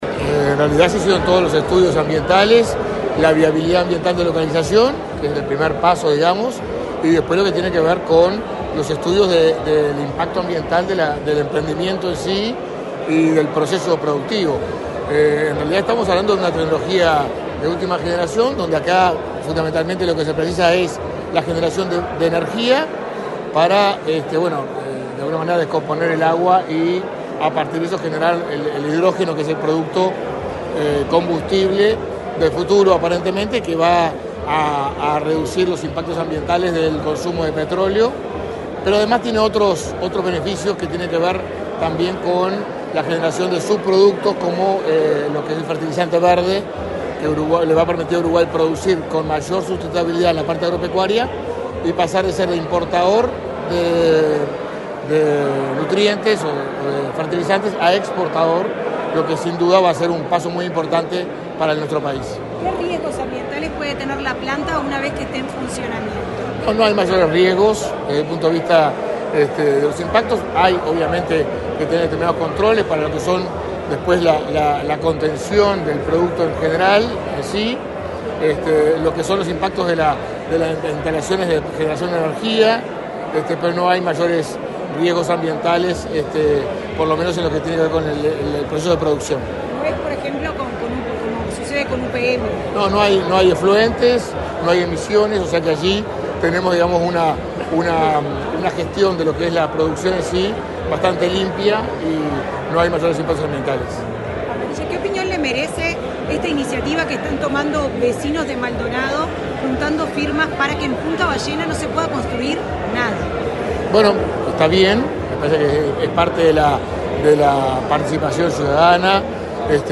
Declaraciones del subsecretario de Ambiente, Gerardo Amarilla
Declaraciones del subsecretario de Ambiente, Gerardo Amarilla 30/10/2024 Compartir Facebook X Copiar enlace WhatsApp LinkedIn Este miércoles 30 en el Laboratorio Tecnológico del Uruguay, el subsecretario de Ambiente, Gerardo Amarilla, dialogó con la prensa, luego de participar en el acto de presentación de la obra de la primera planta de hidrógeno verde del país, proyecto denominado Kahiros.